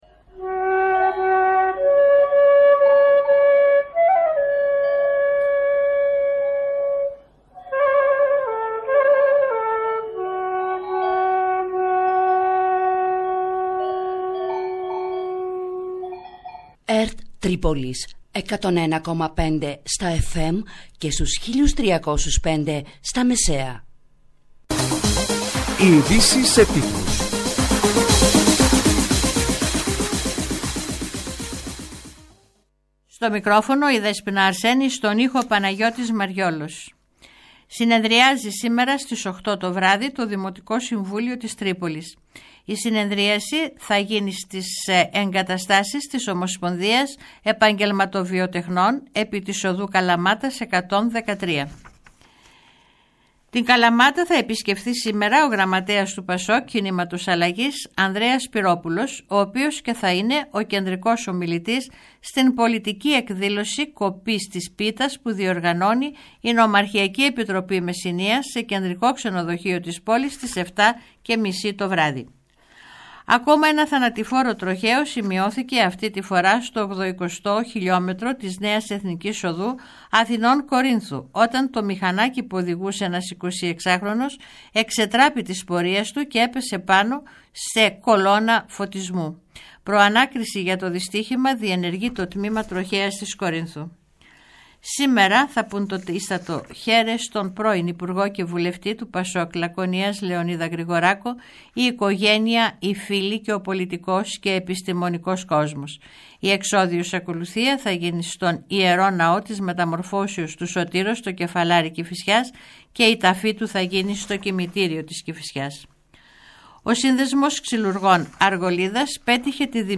μέσα από τη συχνότητα της ΕΡΤ Τρίπολης.